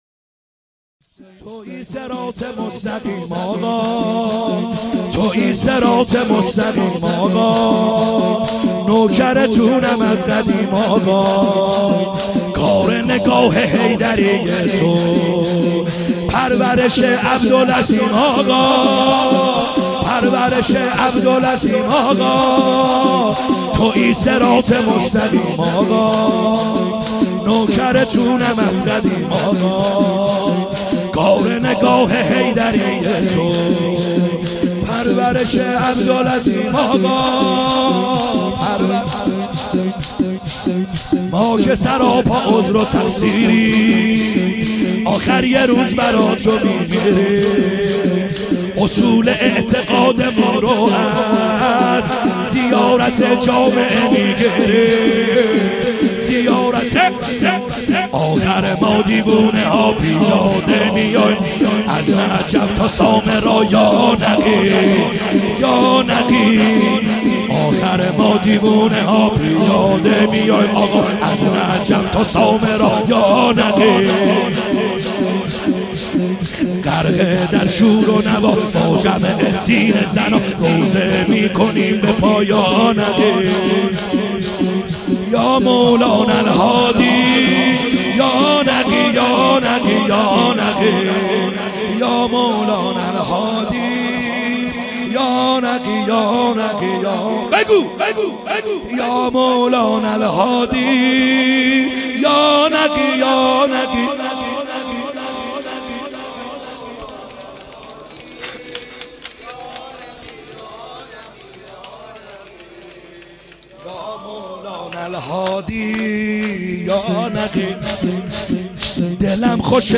خیمه گاه - بیرق معظم محبین حضرت صاحب الزمان(عج) - شور | تویی صراط مستقیم آقا